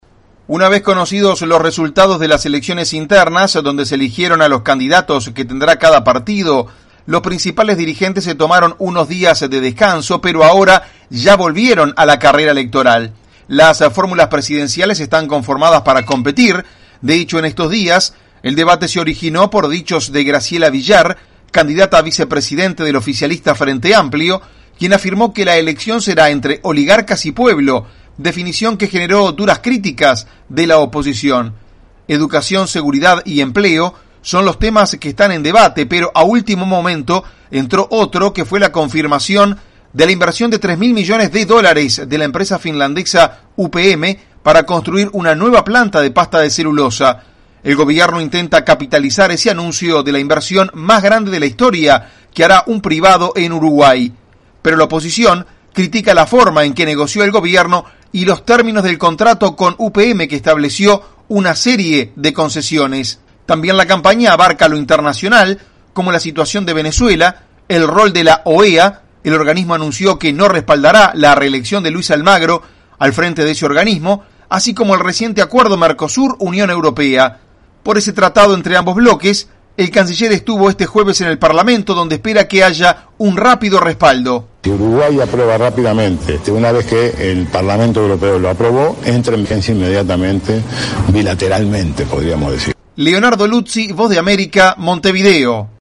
VOA: Informe desde Uruguay